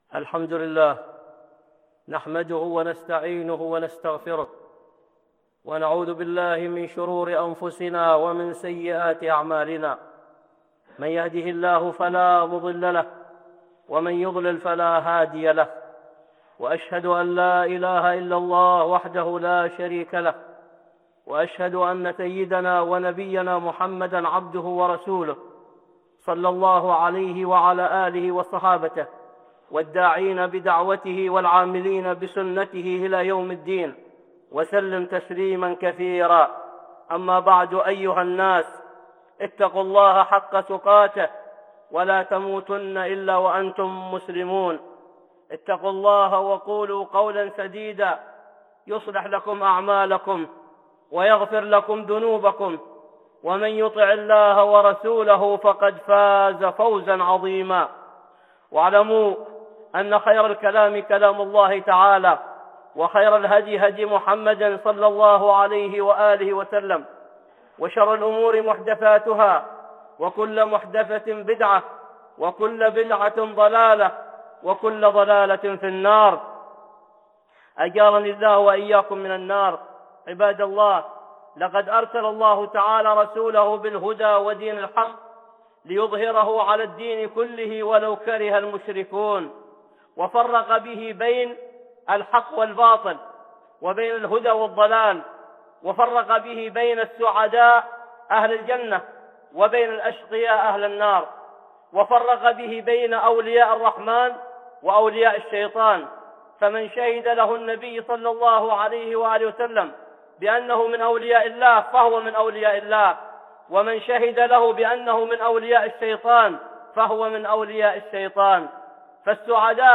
(خطبة جمعة) كرامات الأولياء 1